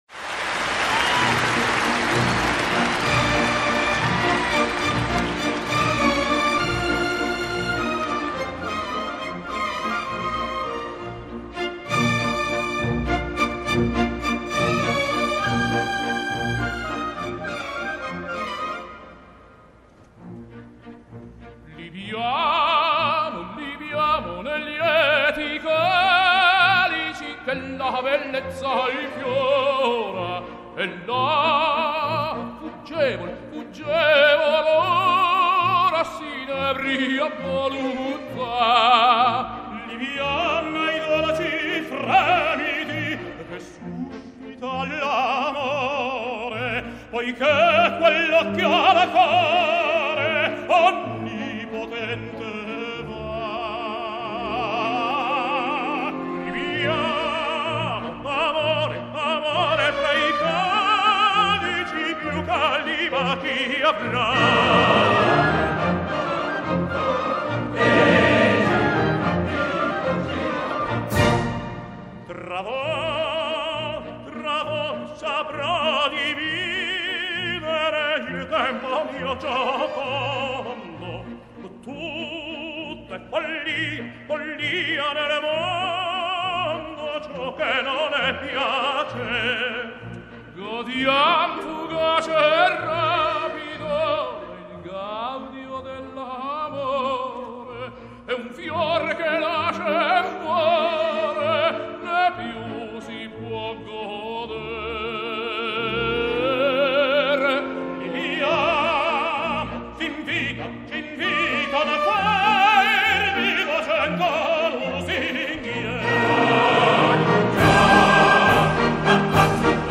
in Concert